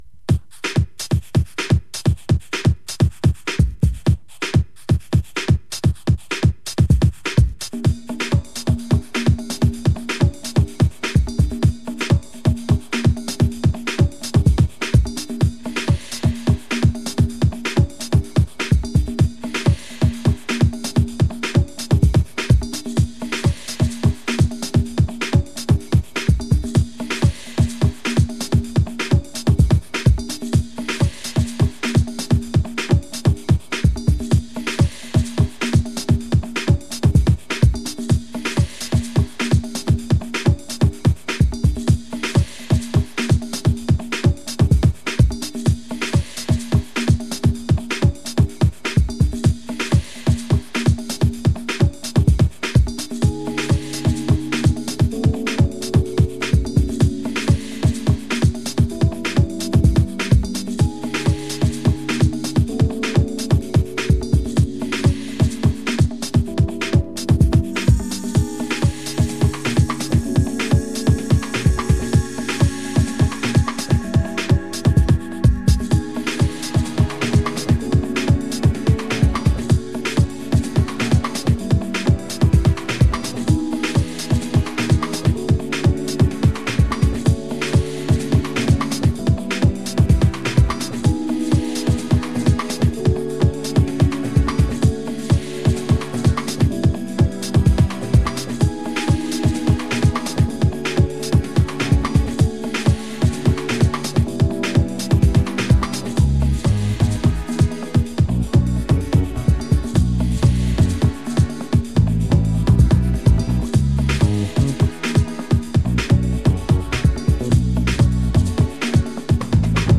モア・センチメンタルな